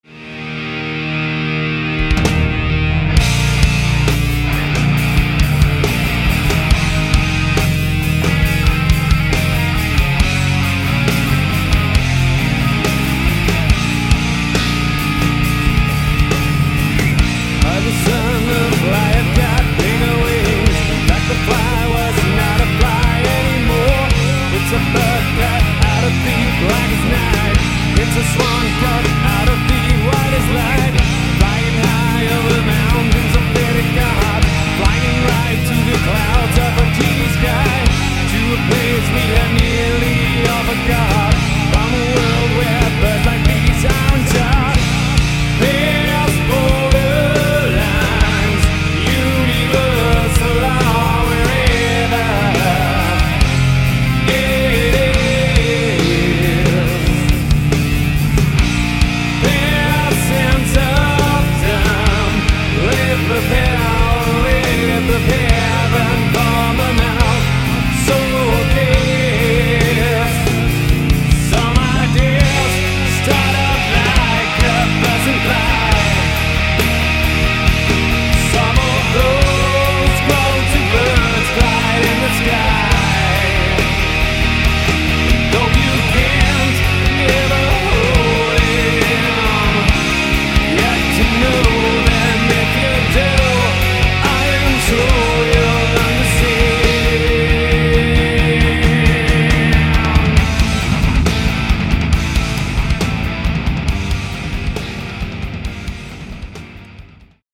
modern, meaningful rock with a darkish twist